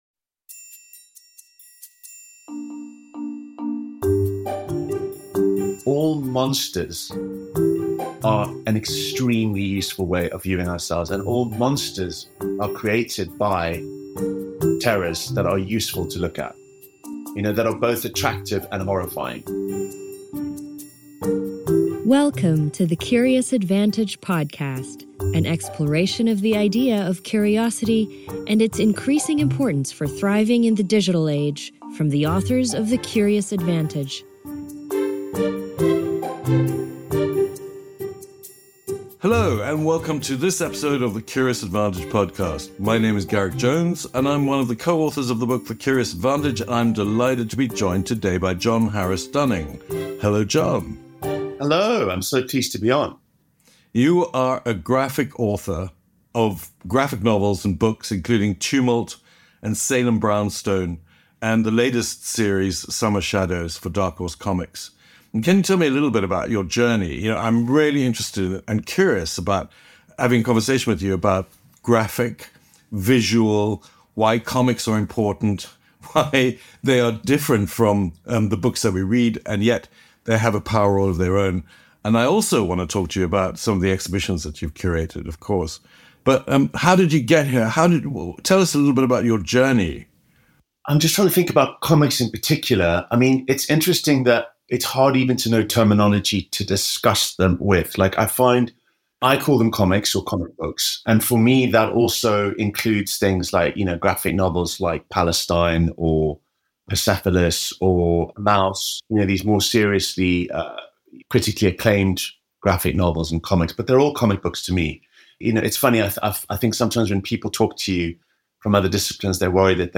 WARNING: STRONG LANGUAGE AND ADULT THEMES .